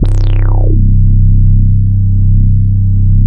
84 BASS 2 -R.wav